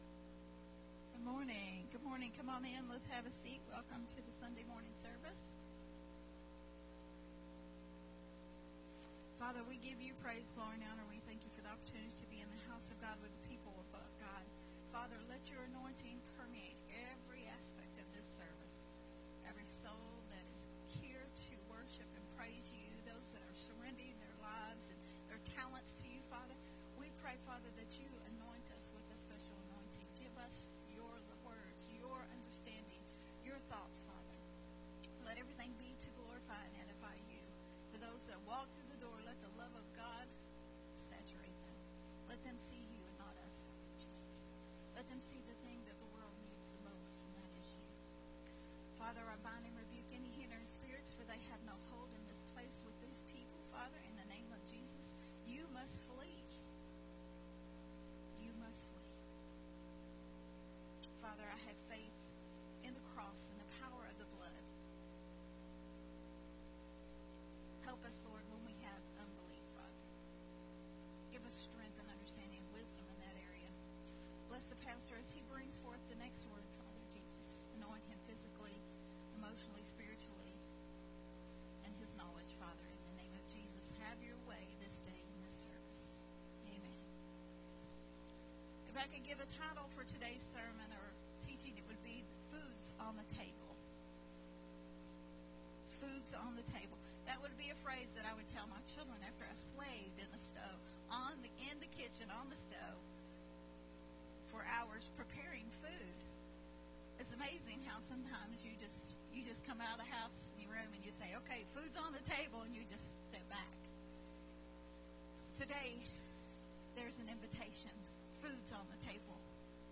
a Sunday Morning Refreshing